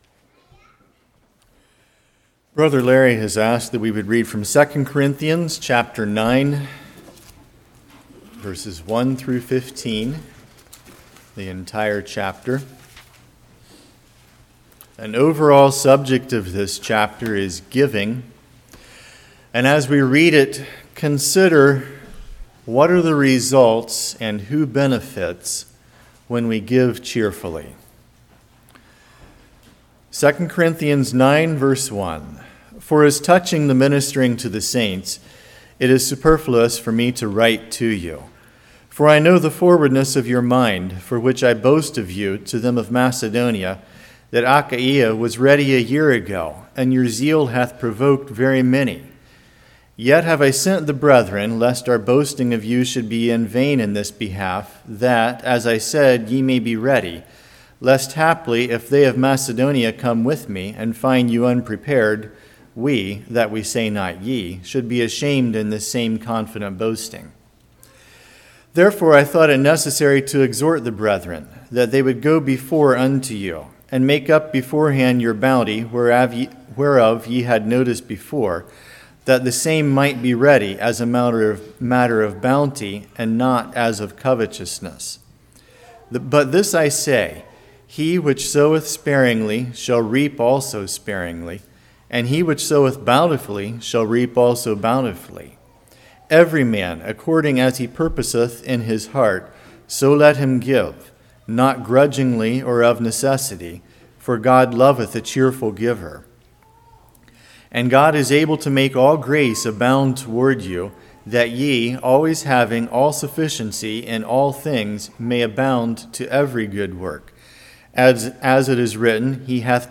Passage: 2 Corinthians 9:1-15 Service Type: Morning